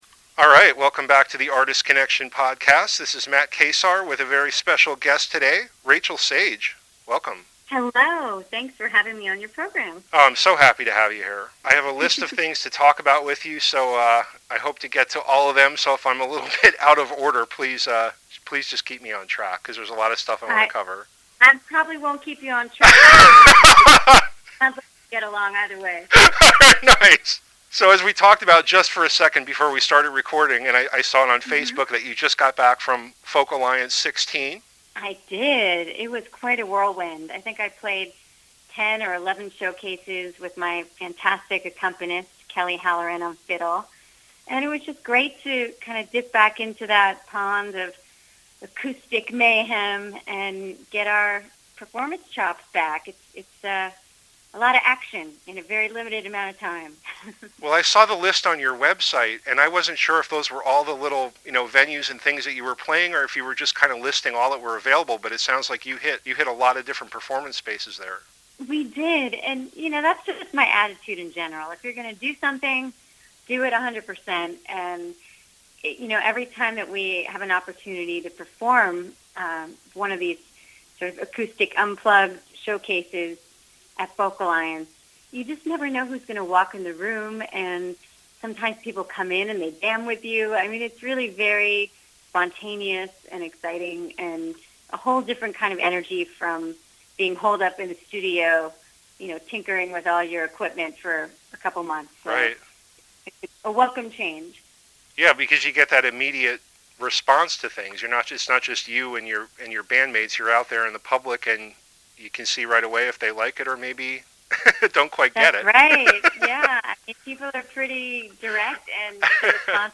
We had a nice conversation about her life, music, and experiences.
Three of my favorite songs are featured with this episode, and I'm looking forward to the new album.